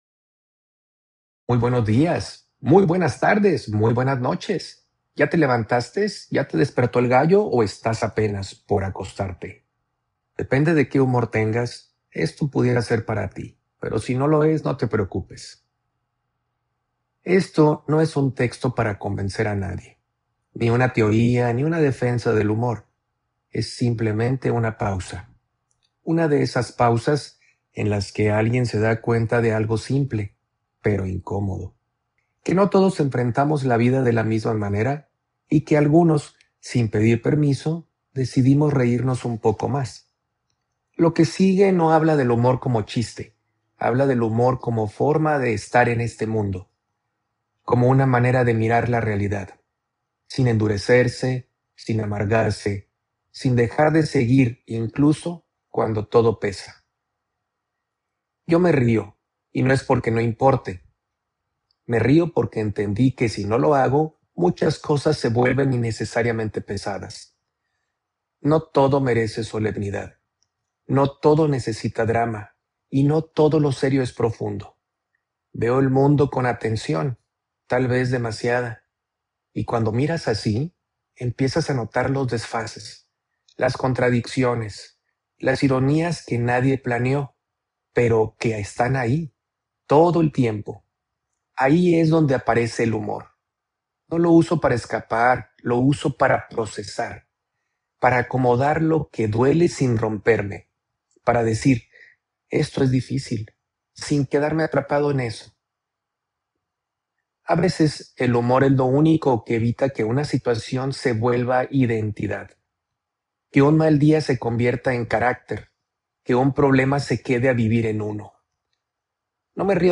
Manifiesto del Humorista Funcional (Version Audio con mi voz y version para leer)